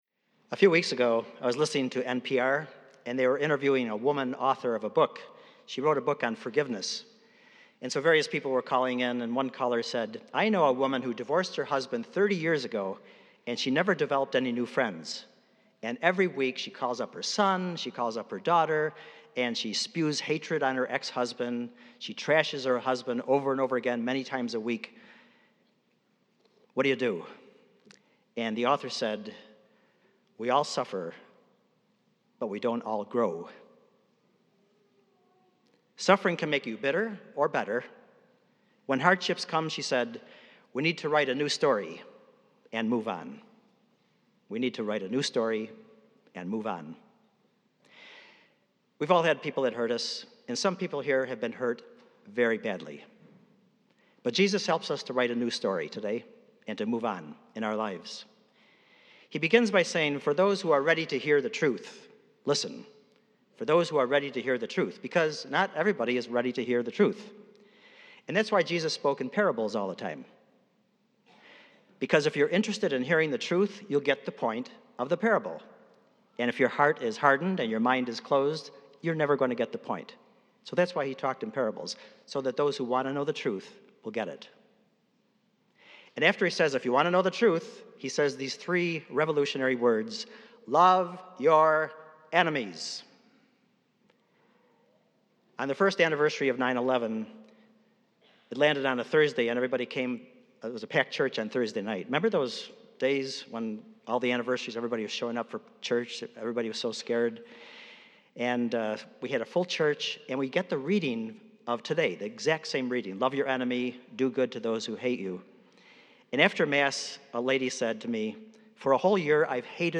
preaches about how loving your enemy will change the world in everyway.